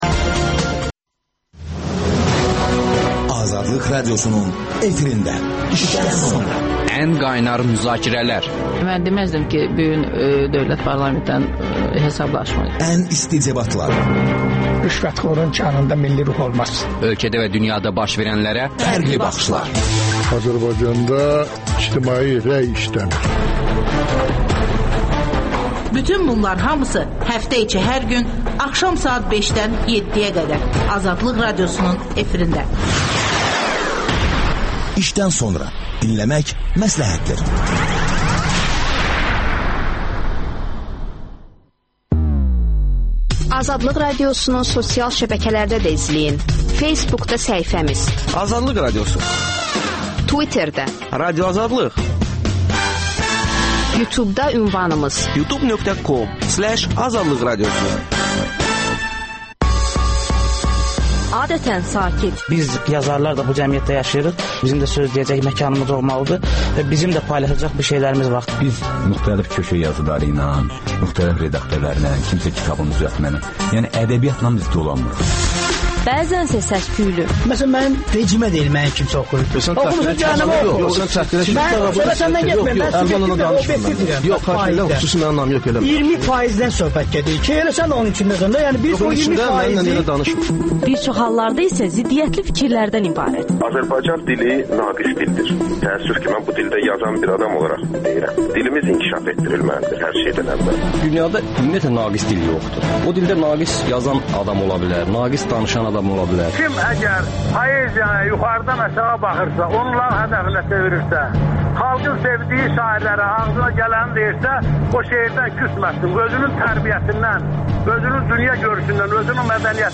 Müsavat Partiyası başqanı İsa Qəmbərlə söhbət